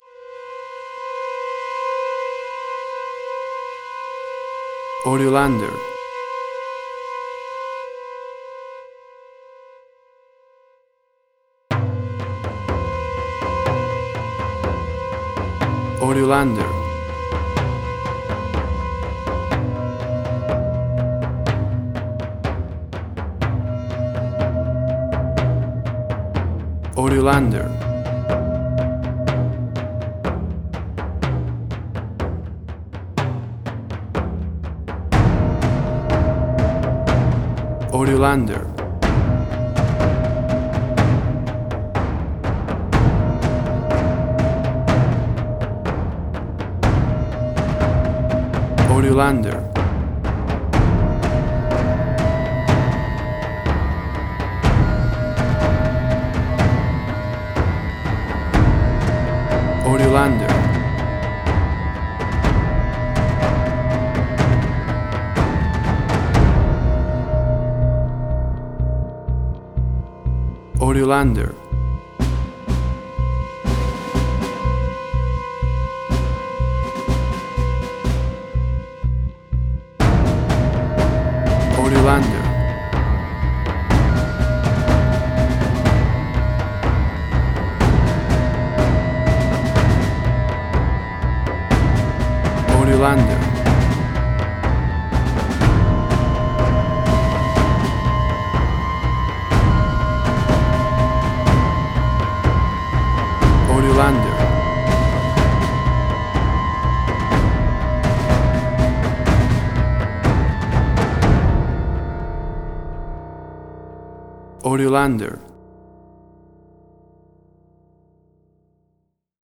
Spaghetti Western
tension, stress, suspense
Tempo (BPM): 62